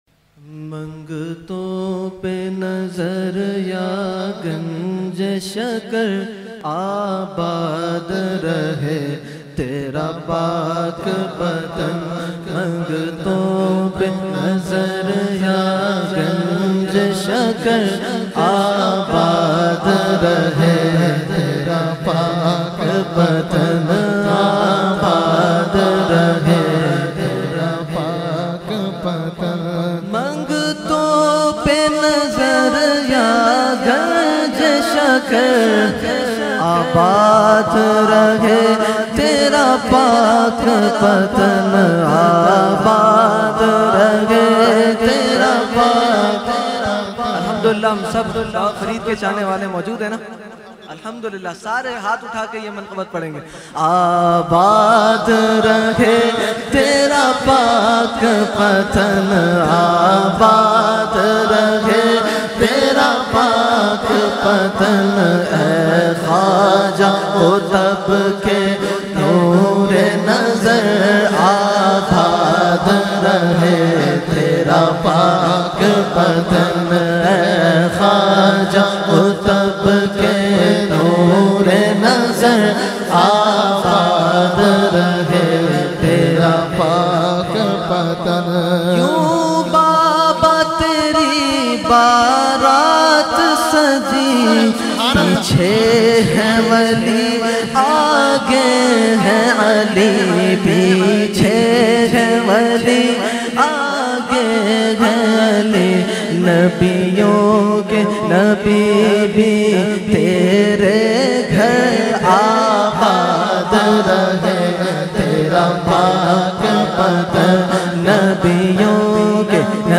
Category : Manqabat | Language : UrduEvent : Muharram 2020
Mangton Pe Nazar Ya Ganj e Shakar - Manqabat Baba Farid.mp3